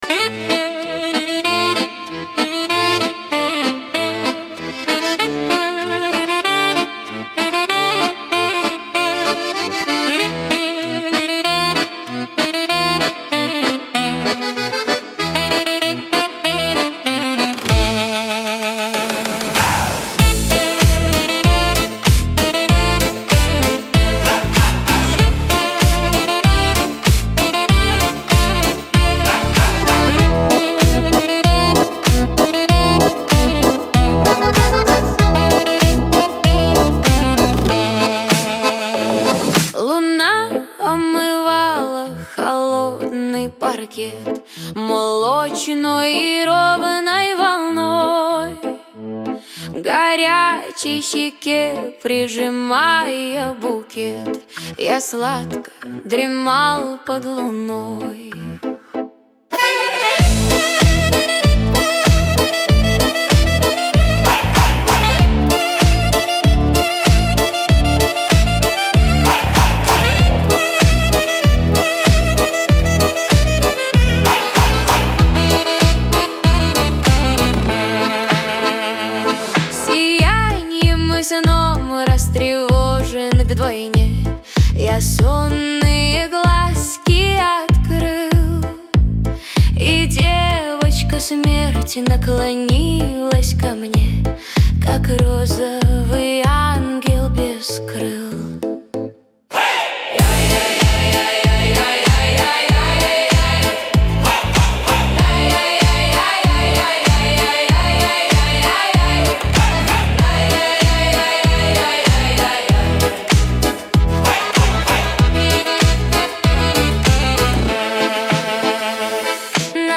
дуэт
эстрада